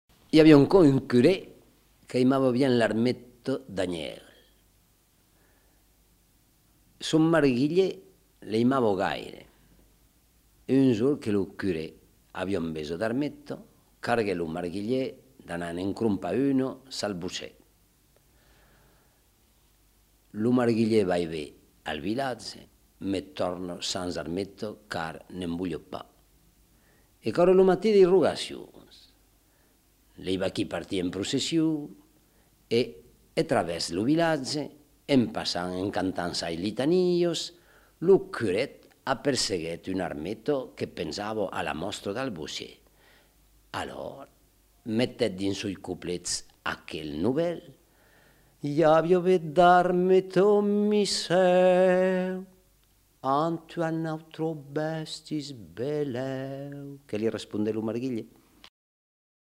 Lieu : Lauzun
Genre : conte-légende-récit
Effectif : 1
Type de voix : voix d'homme
Production du son : parlé